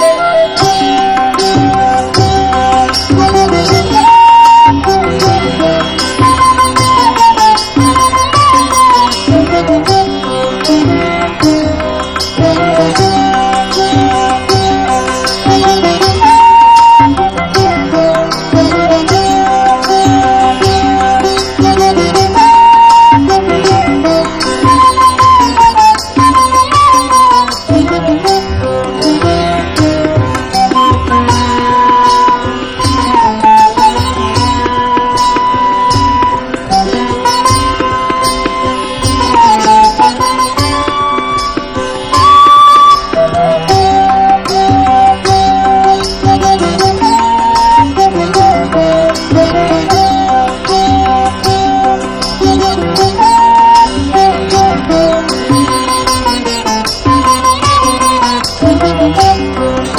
80'S / NEW WAVE
グラマラスで美メロなUKバンド！
弾むベースラインに、チャカチャカ渦巻くカッティング・グルーヴから壮大なストリングス・アレンジへと突入する